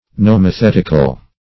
Search Result for " nomothetical" : The Collaborative International Dictionary of English v.0.48: Nomothetic \Nom`o*thet"ic\, Nomothetical \Nom`o*thet"ic*al\, a. [Gr.